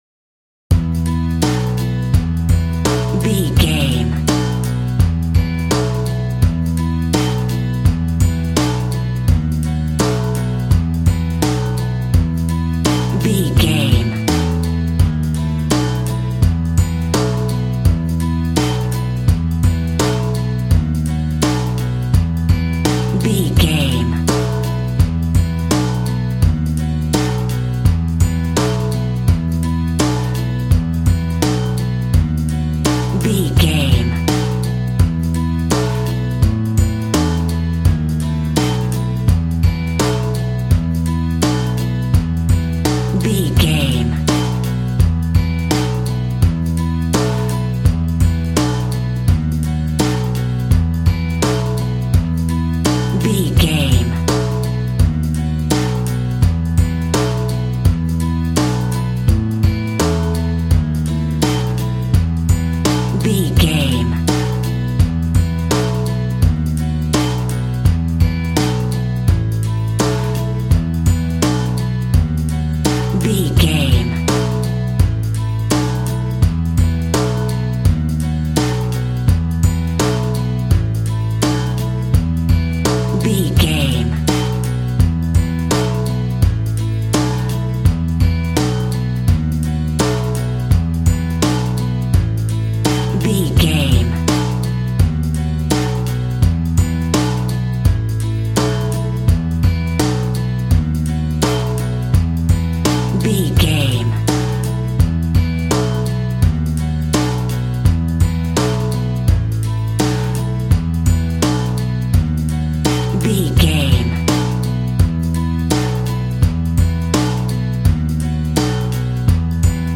Aeolian/Minor
instrumentals
fun
childlike
happy
kids piano